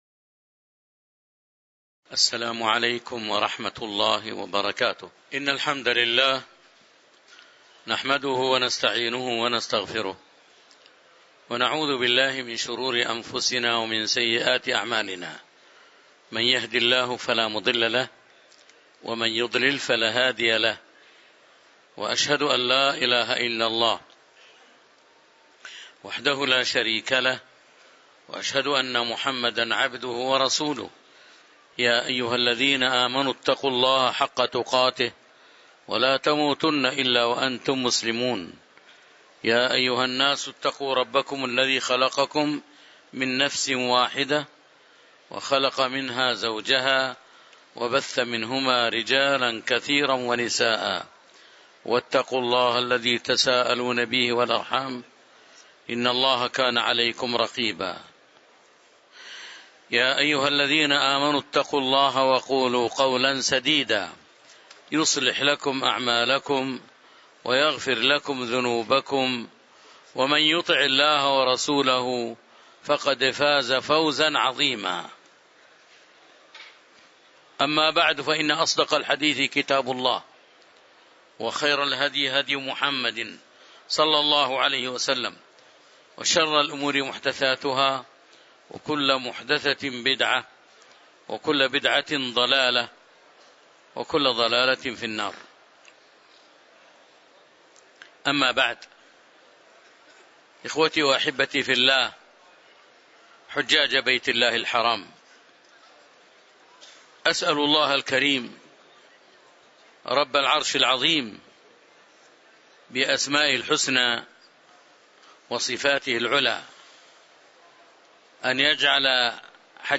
تاريخ النشر ١٧ ذو الحجة ١٤٤٥ المكان: المسجد النبوي الشيخ